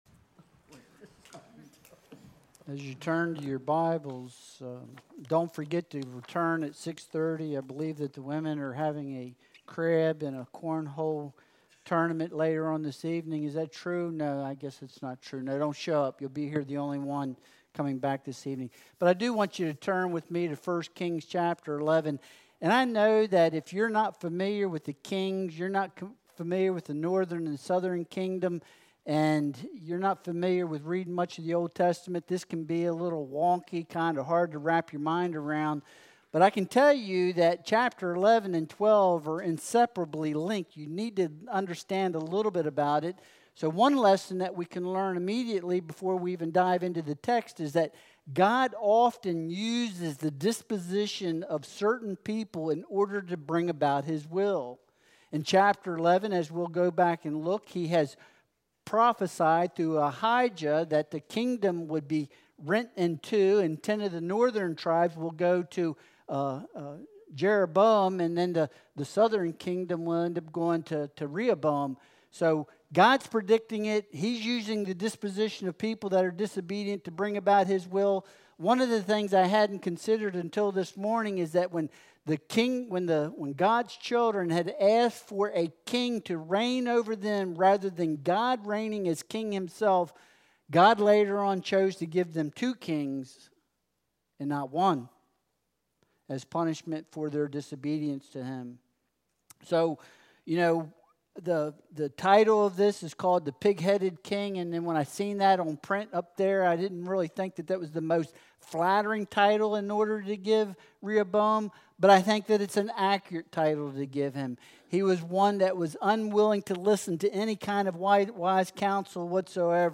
1 Kings 12.1-15 Service Type: Sunday Worship Service Download Files Bulletin « Jesus